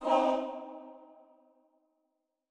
SouthSide Chant (52).wav